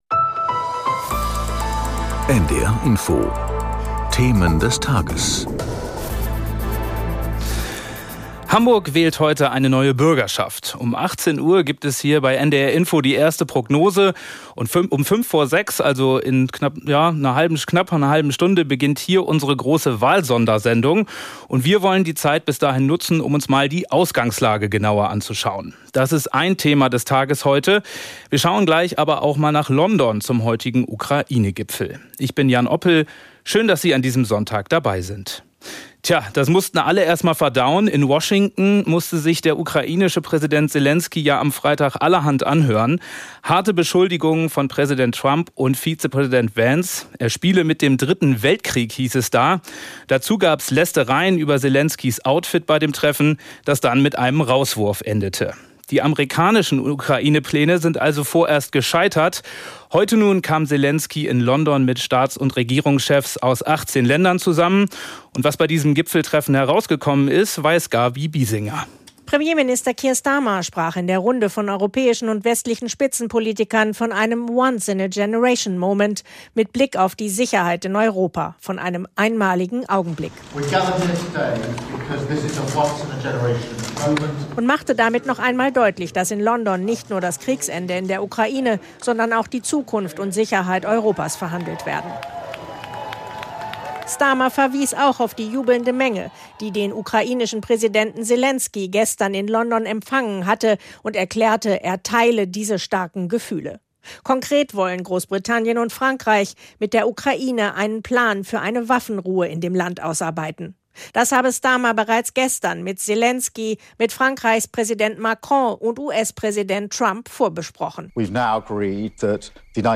In Interviews mit Korrespondenten, Experten oder Politikern.